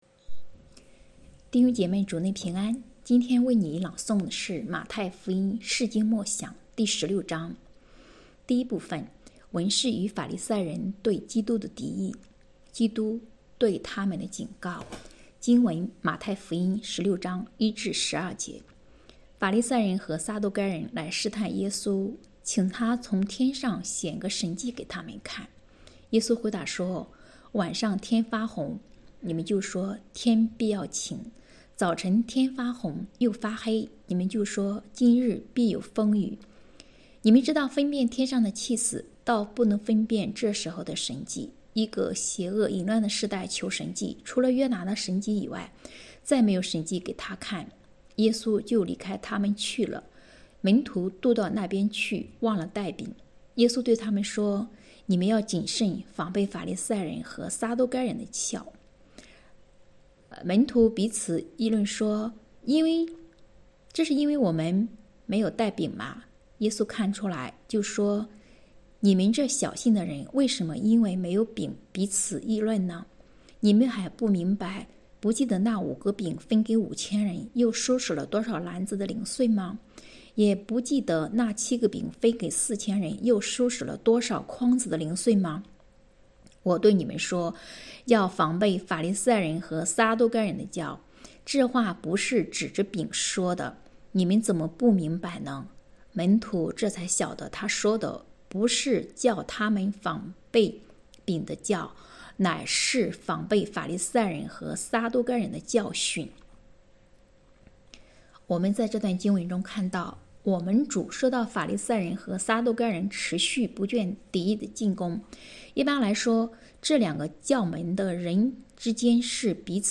“伴你读书”，正在为您朗读：《马太福音释经默想》 欢迎点击下方音频聆听朗读内容 https